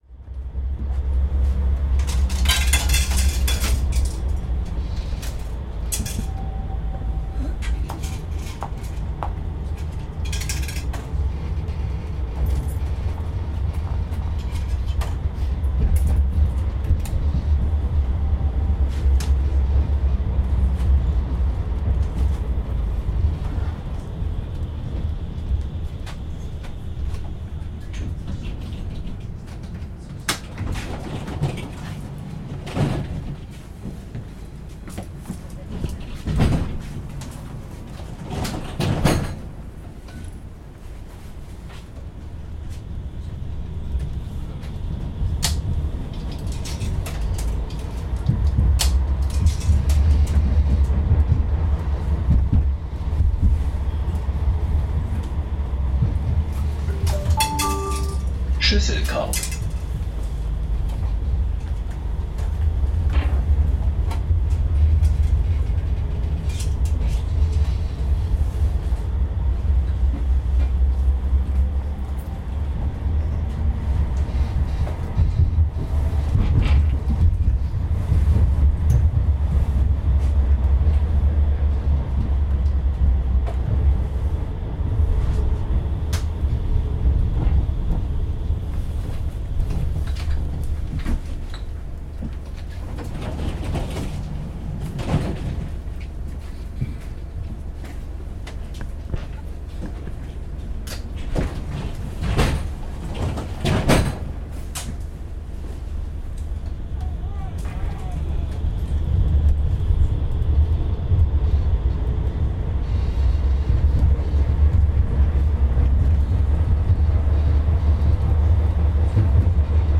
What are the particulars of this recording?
Recorded by Cities and Memory - a trip aboard the number 6 tram from the Hauptbahnhof towards the airport in Bremen, Germany, a city where the sound of trams is a key part of the everyday soundscape.